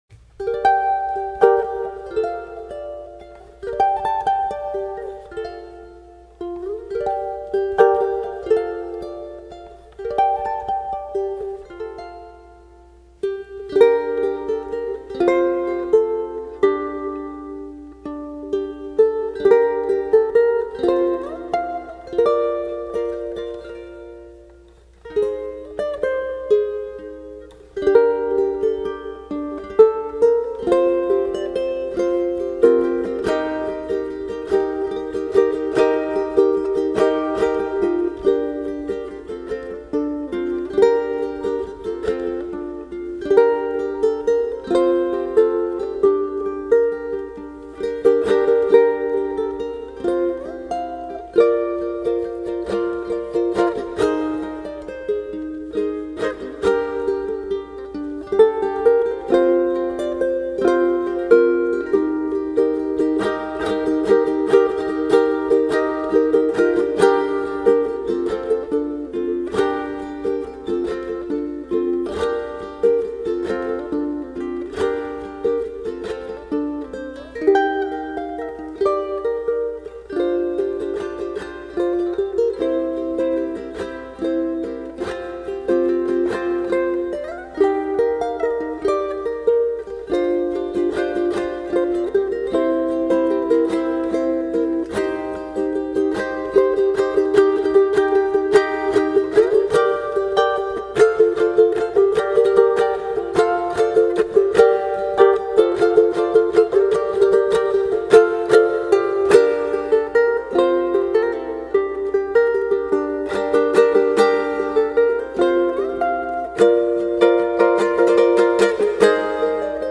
23 inch Ranch Concert ukulele voice
21 inch Ranch Soprano ukulele voice
Ranch-Ukulele.mp3